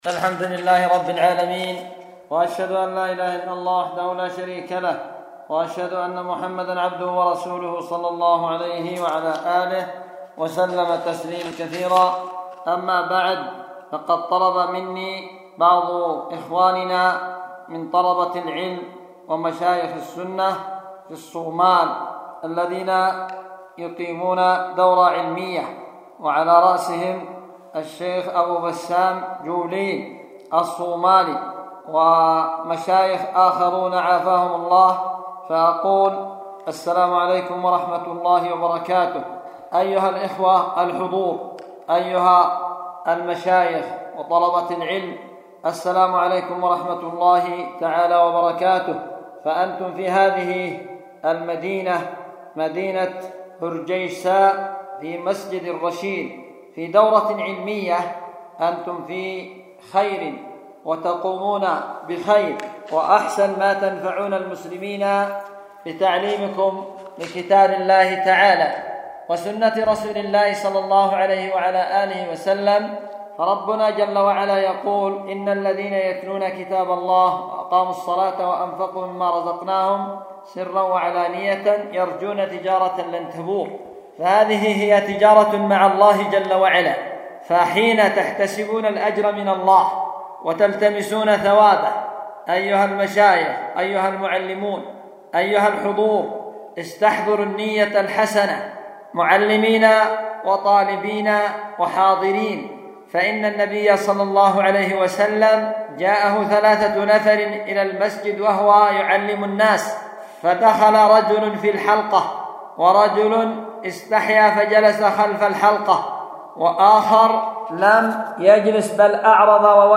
كلمة توجيهية لطلاب الدورة التأصيلية المقامة في مسجد الرشيد بمدينة هرجيسا في الصومال